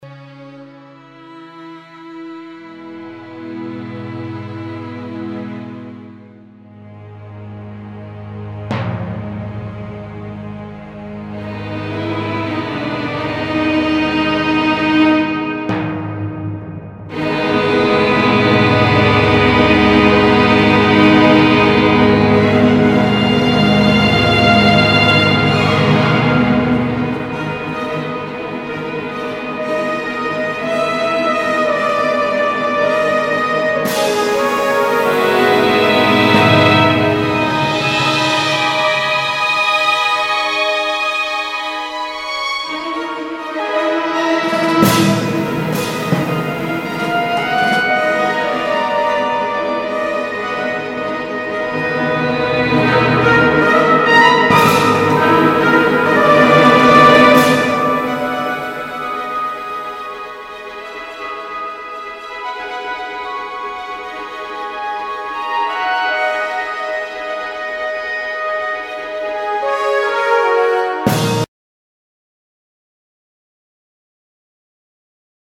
(Epic / Drama / Mysterious)